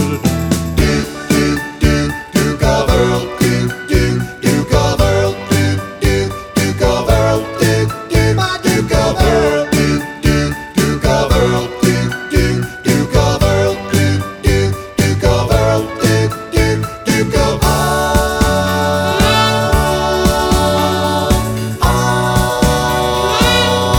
No Intro Ooohs Rock 'n' Roll 3:45 Buy £1.50